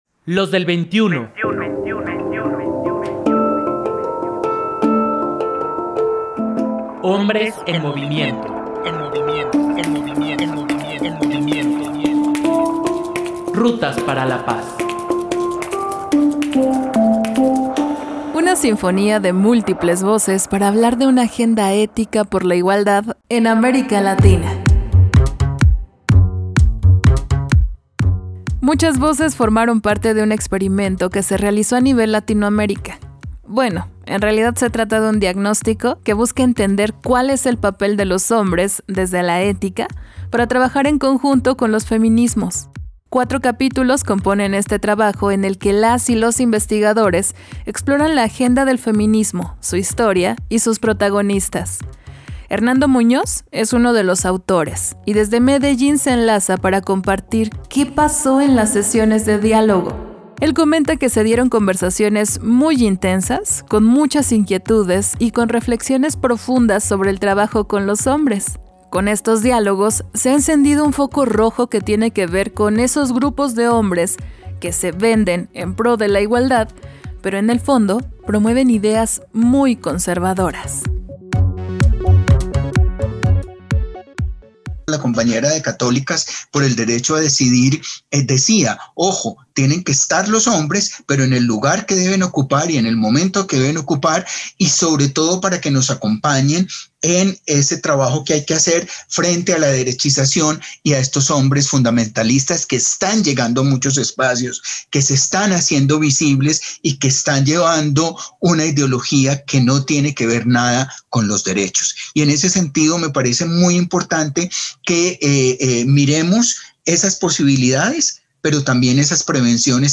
Una sinfonía de múltiples voces para hablar de una agenda ética por la igualdad en América Latina